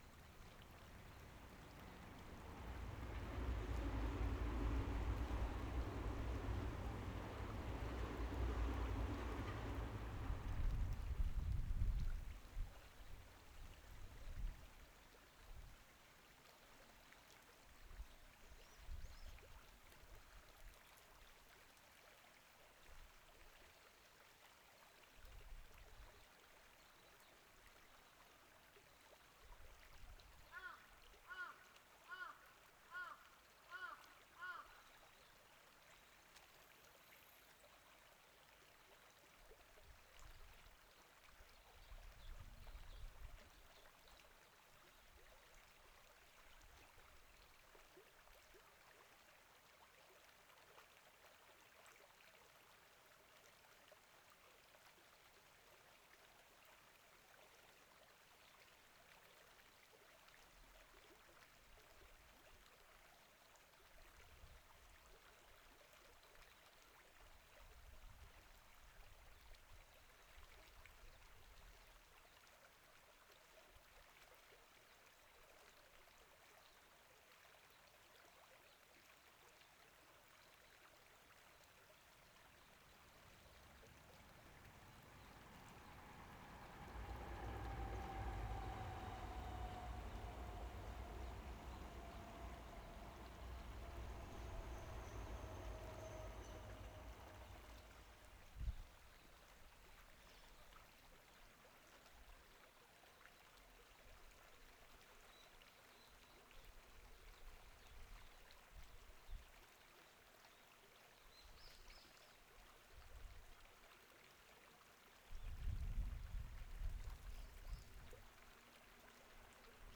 「黒目川の流れ」　2020年5月24日
スピーカー用のサランネットをウインドスクリーンとして被せました。
ECM-99Aは、PCM-D10で録音
どちらのマイクも、風による吹かれ音が入ります。
右側には西武線が通っています。